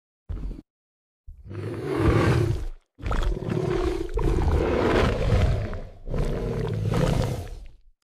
Tiger Roar Wildlife Sfx Téléchargement d'Effet Sonore
Tiger Roar Wildlife Sfx Bouton sonore